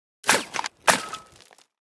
ShovelDig.wav